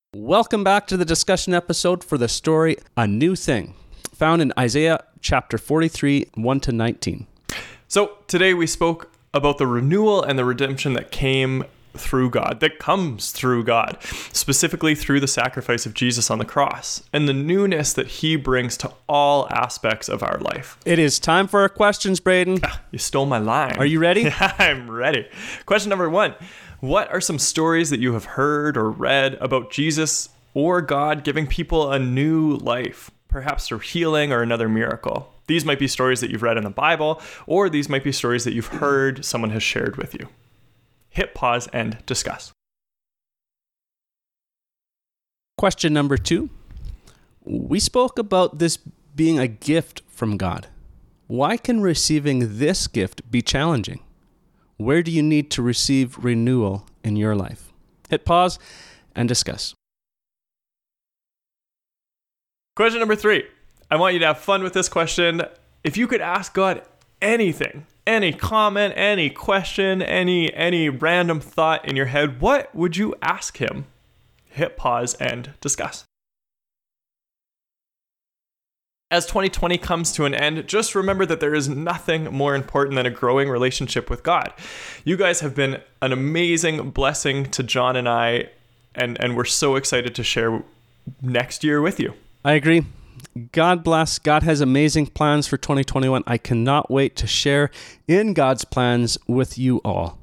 Discussion | A New Thing